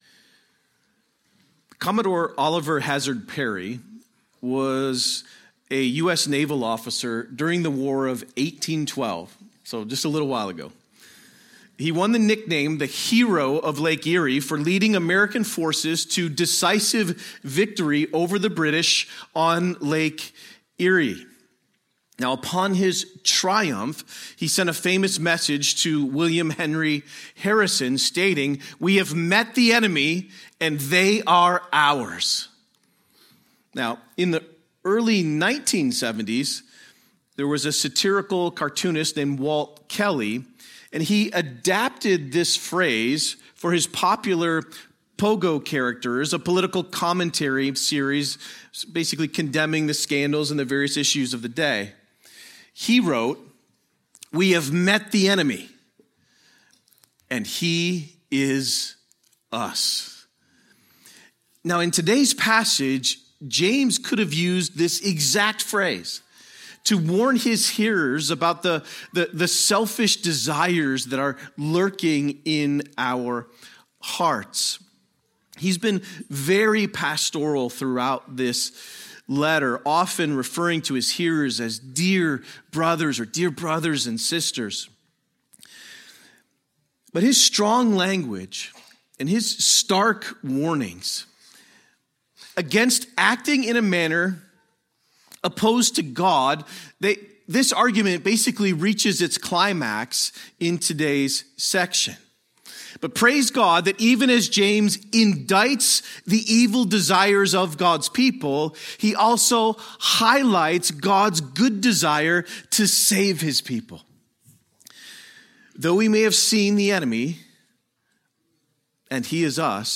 A sermon on James 4:1-5